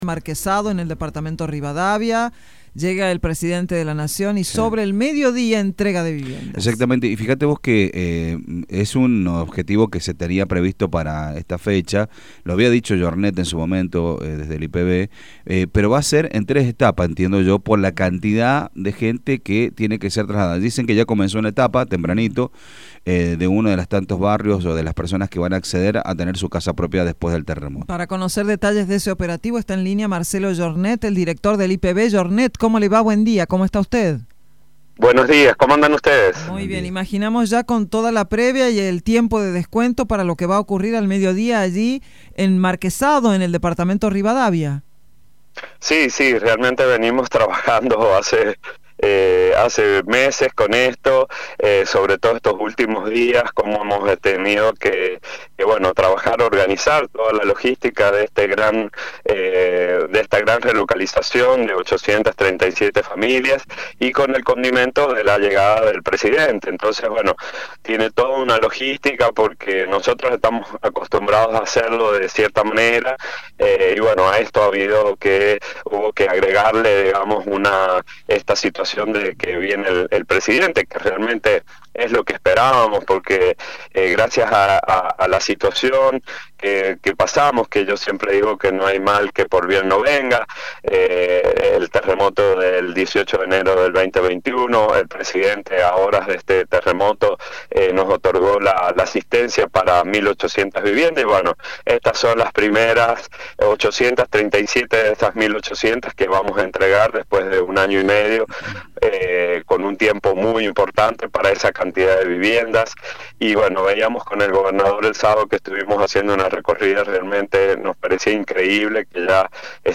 Marcelo Yornet, director del IPV, dialogó con los periodistas de Radio Sarmiento para brindar detalles sobre la entrega.